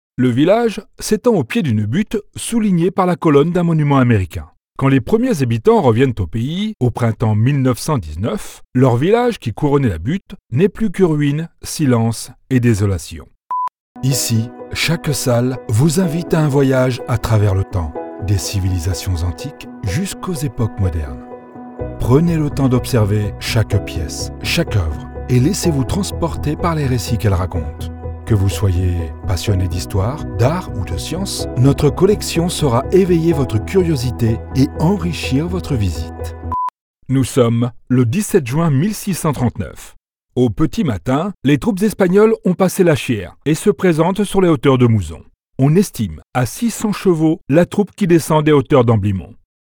Deep, Natural, Warm, Soft, Commercial, Versatile
Audio guide